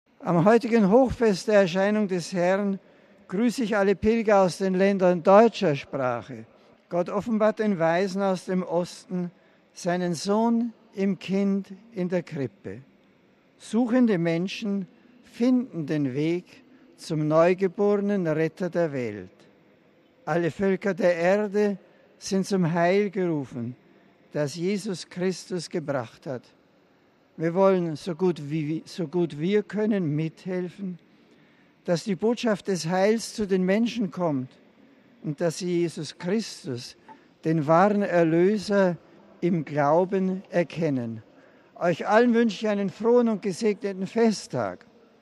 Das meinte der Papst heute Mittag nach der großen Messfeier im Petersdom beim Angelusgebet auf dem Petersplatz.
wandte er sich auch an seine Landsleute - hören Sie seinen Text in unserem Audio-File.